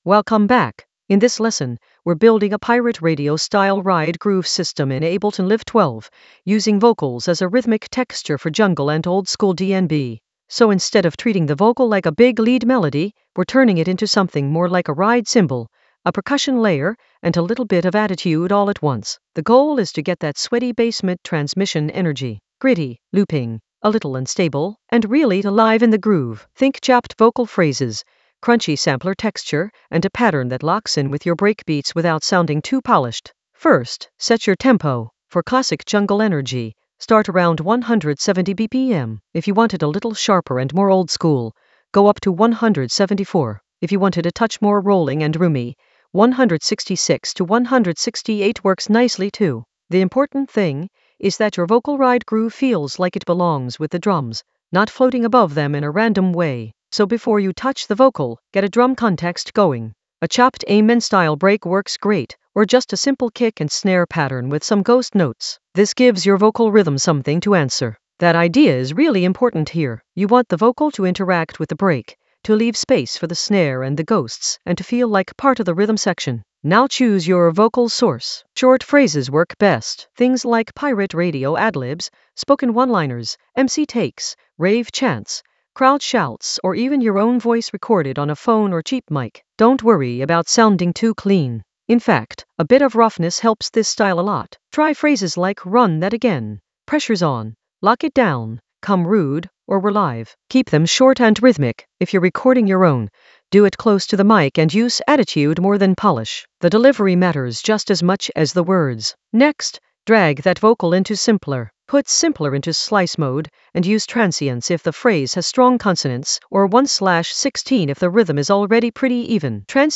An AI-generated intermediate Ableton lesson focused on Pirate Radio Ableton Live 12 ride groove system with crunchy sampler texture for jungle oldskool DnB vibes in the Vocals area of drum and bass production.
Narrated lesson audio
The voice track includes the tutorial plus extra teacher commentary.